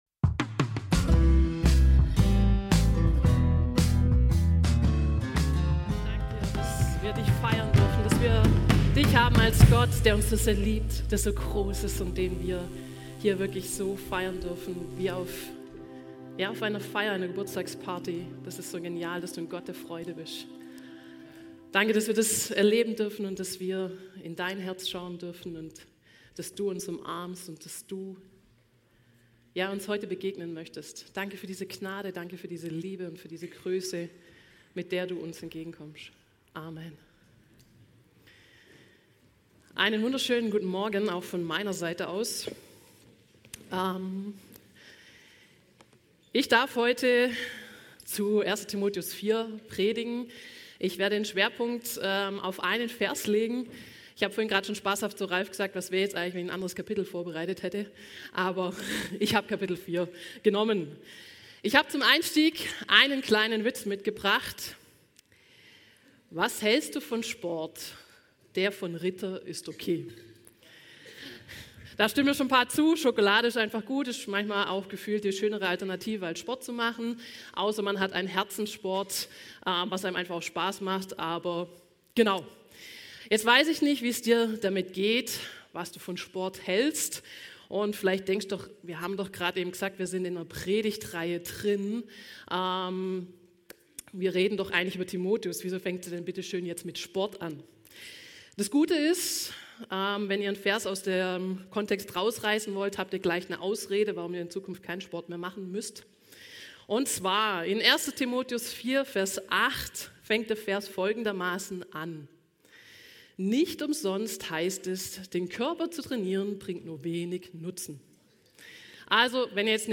alle Predigten Die Ehrfurcht vor Gott 26 Oktober, 2025 Serie: 1.